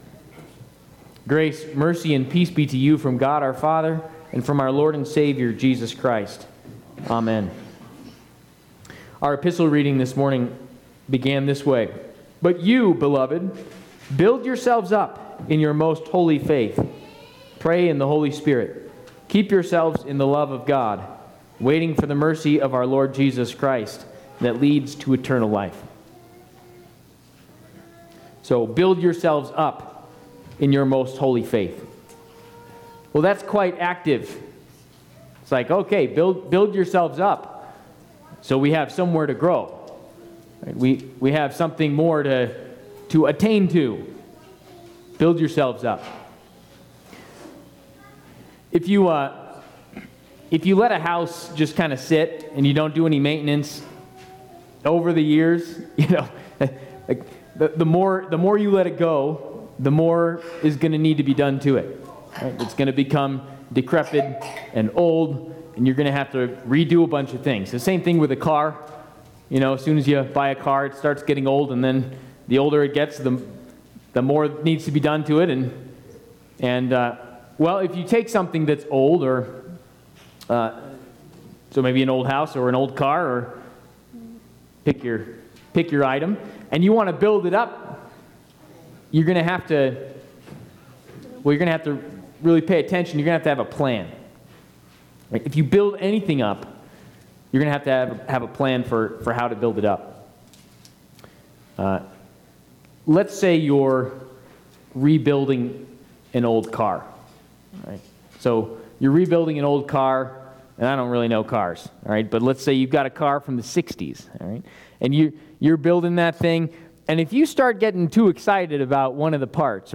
Trinity Lutheran Church, Greeley, Colorado Stay Awake, Stay Alert, Be Watchful Nov 24 2024 | 00:15:30 1x 00:00 / 00:15:30 Subscribe Share RSS Feed Share Link Embed